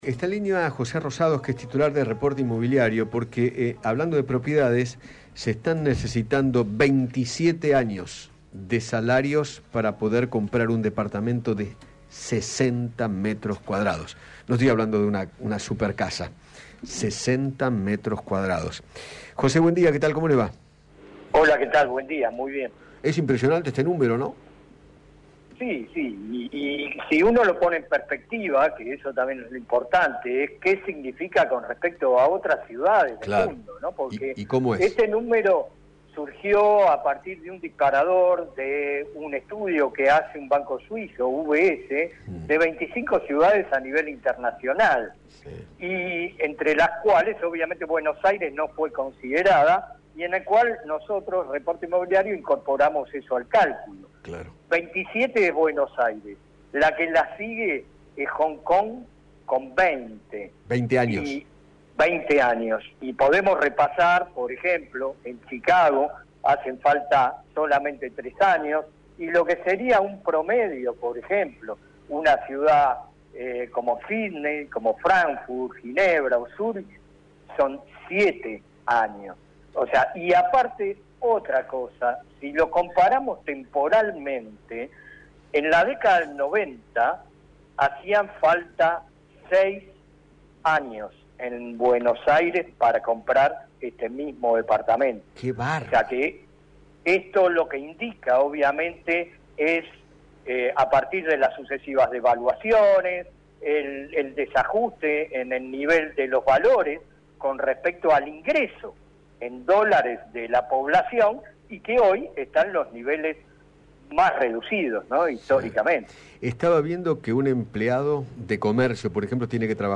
dialogó con Eduardo Feinmann sobre el alarmante dato que surgió de cálculos elaborados por la consultora